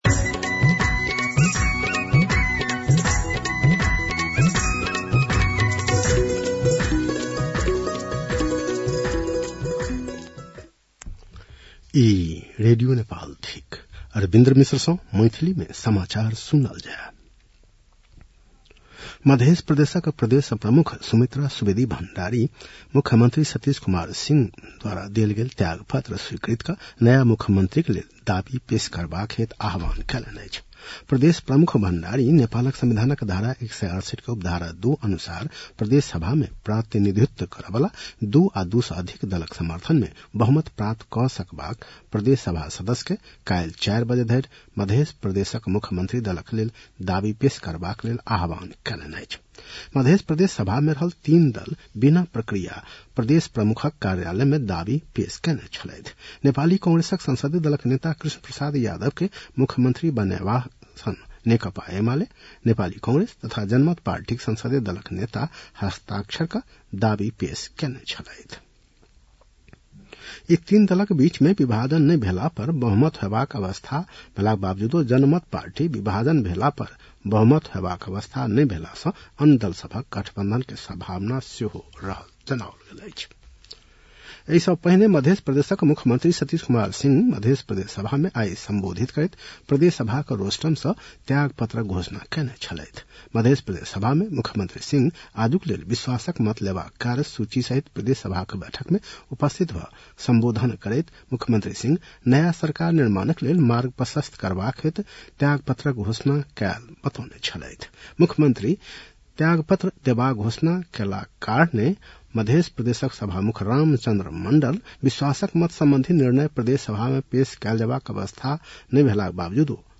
मैथिली भाषामा समाचार : २८ असोज , २०८२